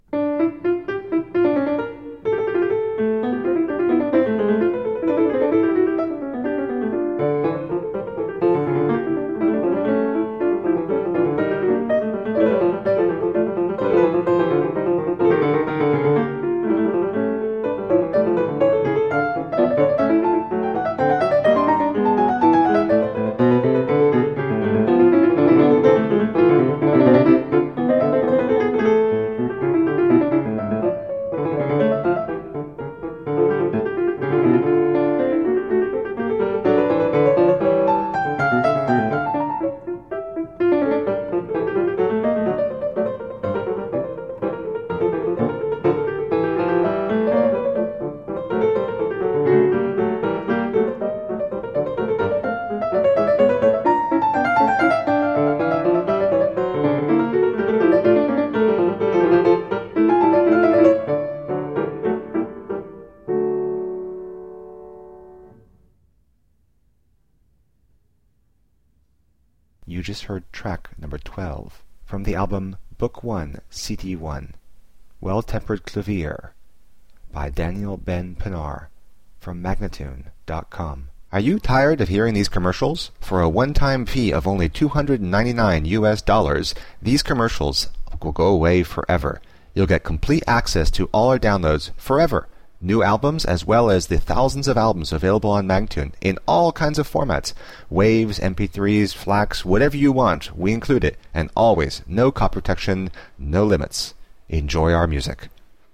Virtuoso pianist
Classical, Baroque, Instrumental Classical, Classical Piano